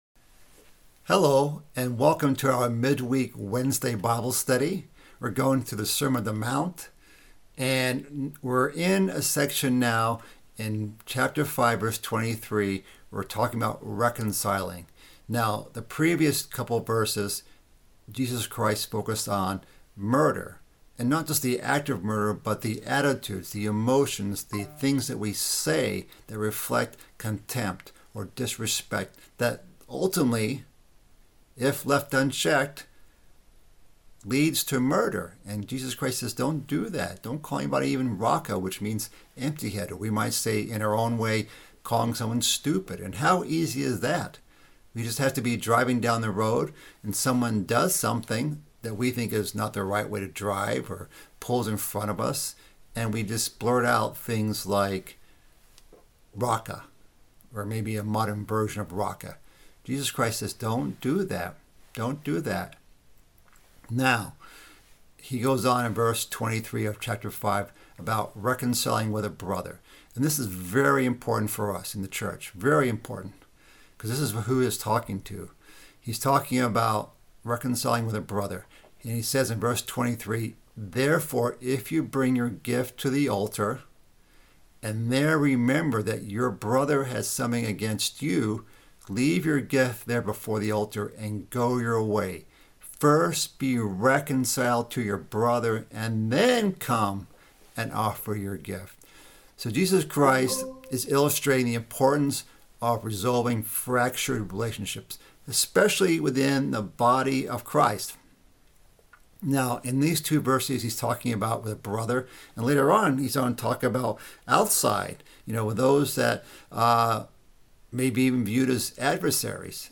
This is part of a mid-week Bible study series covering the sermon on the mount. This week's study covers the topic of resolving conflict, as well as the depth of God's command to not commit adultery.